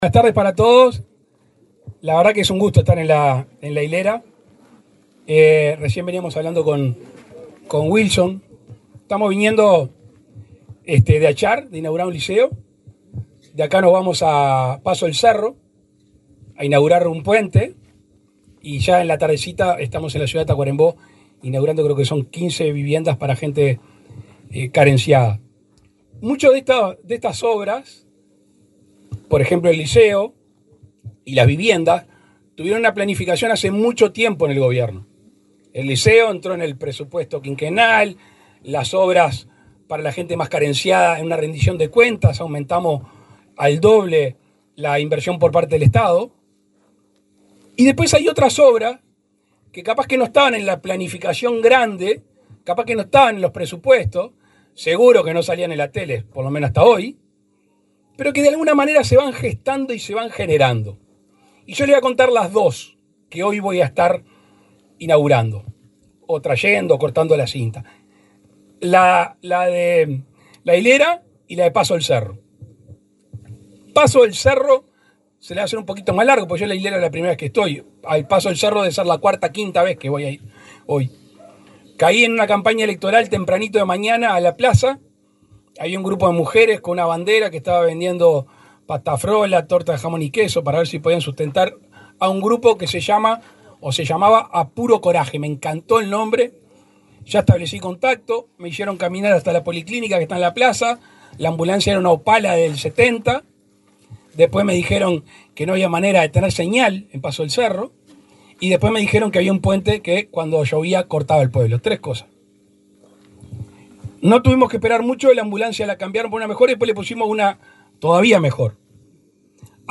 Palabras el presidente Luis Lacalle Pou
El presidente Luis Lacalle Pou encabezó, este lunes 2, el acto de entrega de una ambulancia de ASSE a la policlínica de La Hilera, en el departamento